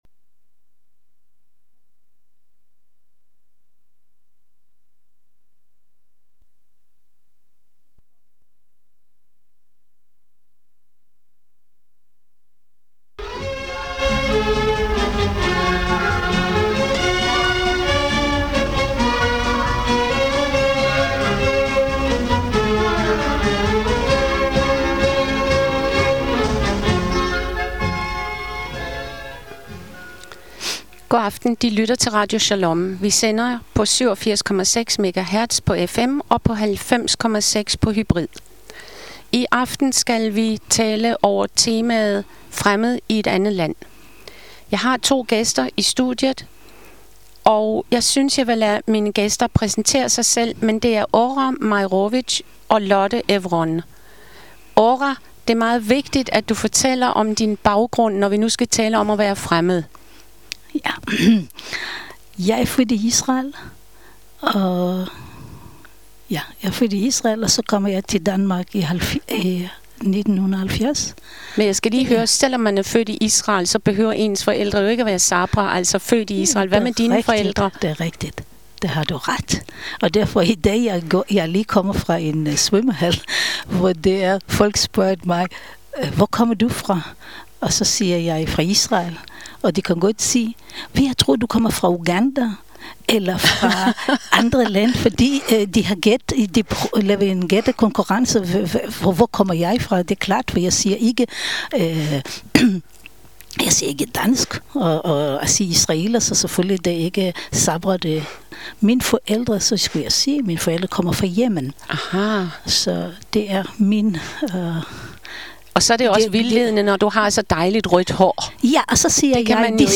Diskussion over emnet at være fremmed i eget land